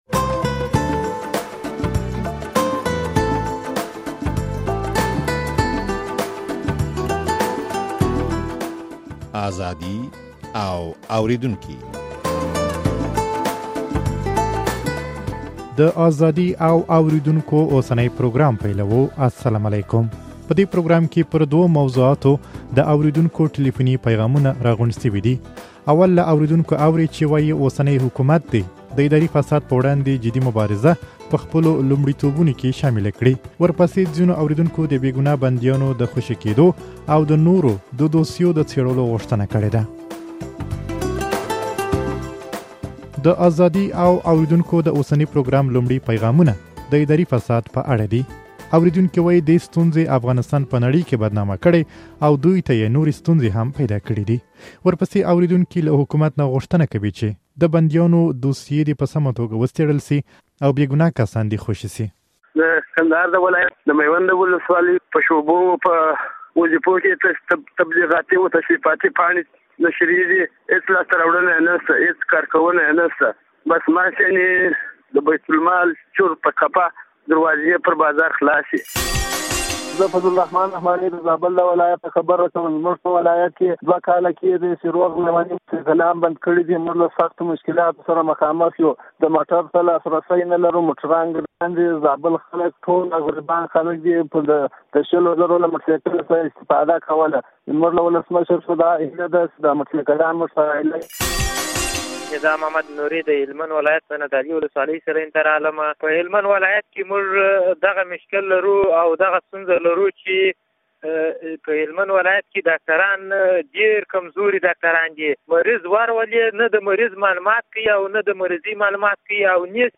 په دې پروګرام کې پر دوو موضوعاتو د اورېدونکو ټليفوني پيغامونه راغونډ شوي دي.